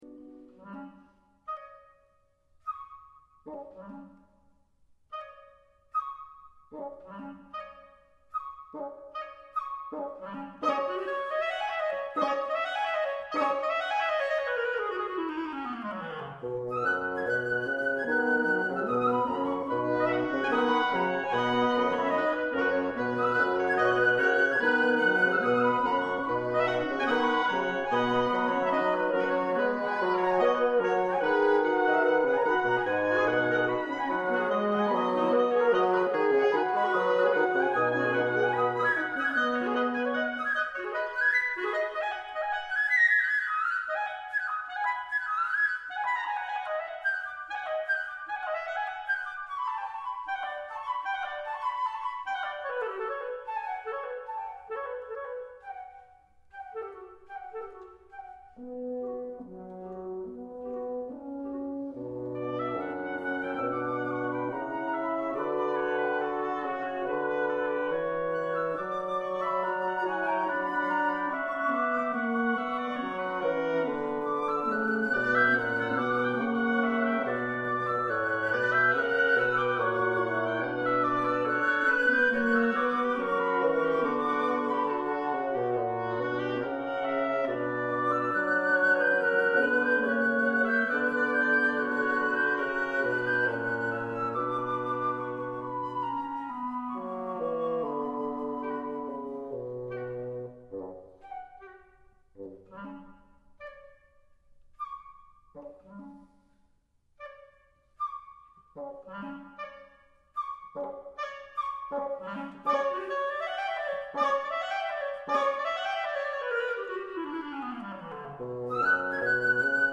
- Audición musical:
Divertimento para Quinteto de Viento de E. Larsson.